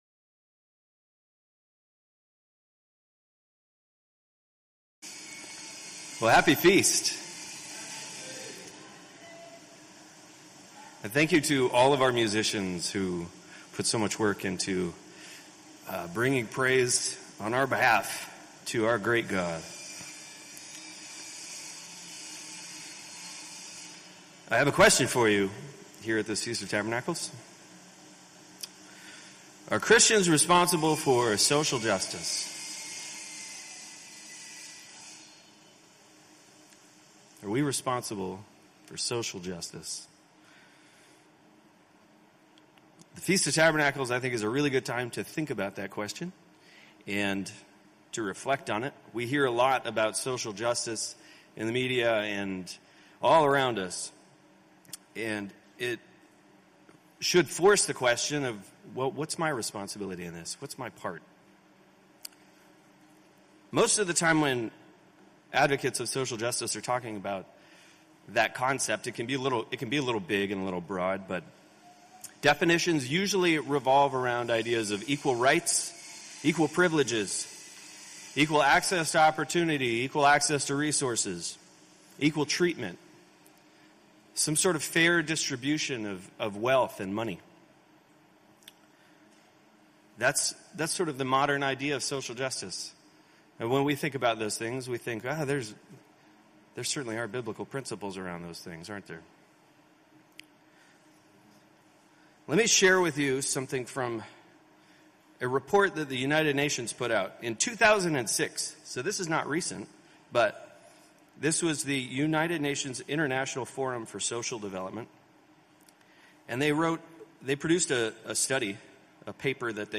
This sermon was given at the Montego Bay, Jamaica 2022 Feast site.